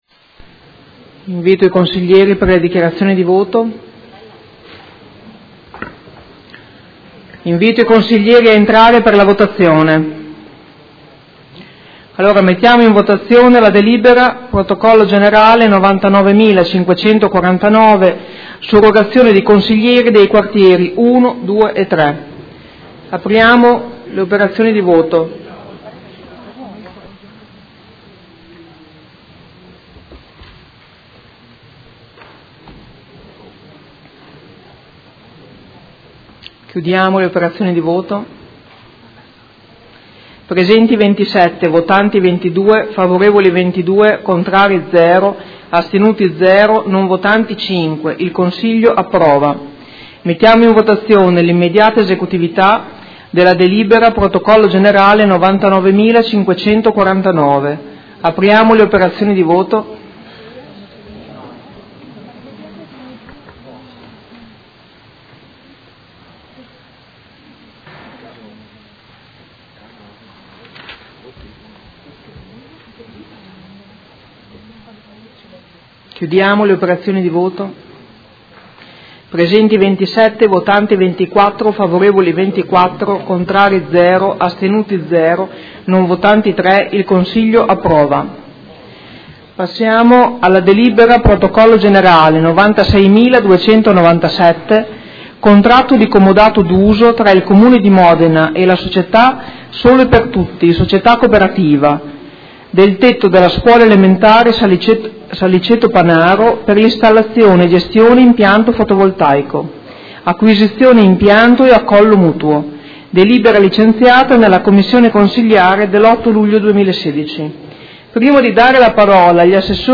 Seduta del 14/07/2016 Mette ai voti la Delibera: Surrogazione di consiglieri dei Quartieri 1, 2 e 3.
Presidentessa